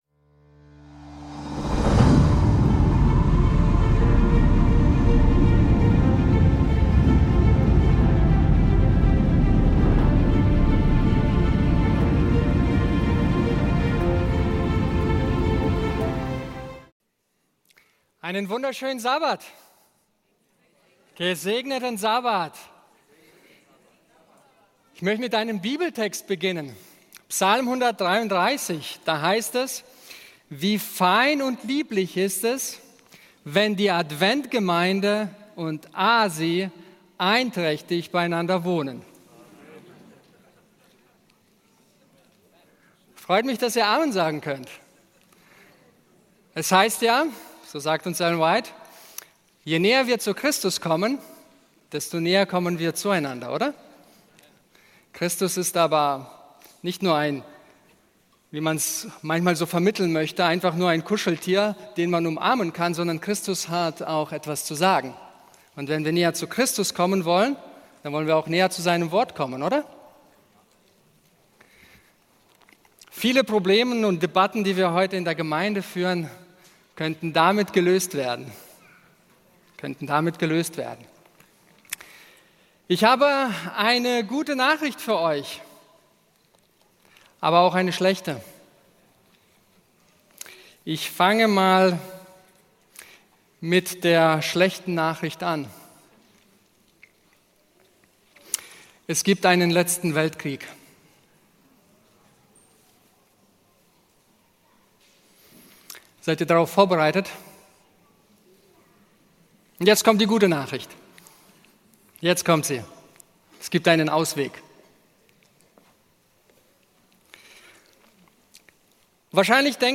In diesem packenden Vortrag wird die prophetische Aussicht auf den letzten Weltkrieg enthüllt. Ein intensiver Konflikt zwischen König Nord und König Süd steht bevor, während die Welt in Abkehr von Gottes Geboten taumelt. Mit einem eindringlichen Aufruf zur Umkehr werden die Zuhörer ermutigt, sich für die Wahrheit und das Wort Gottes einzusetzen.